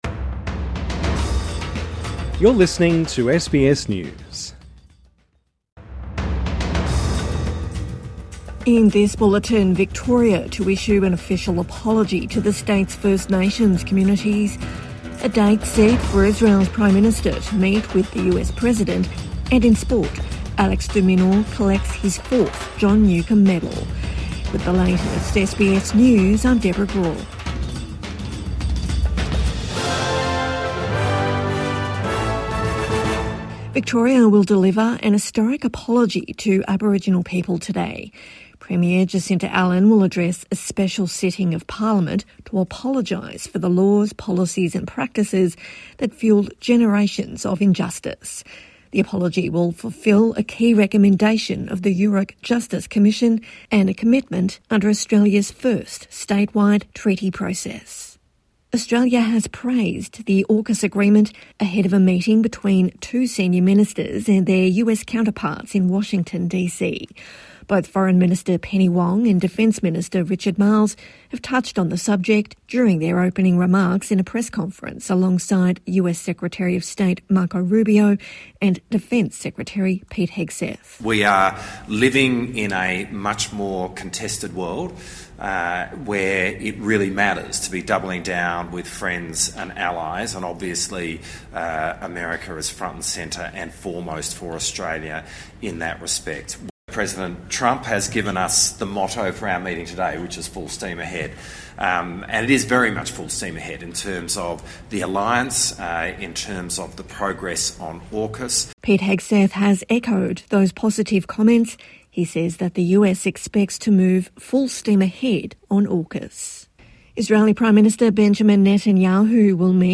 Victoria to issue apology to state's Indigenous community | Midday News Bulletin 9 December 2025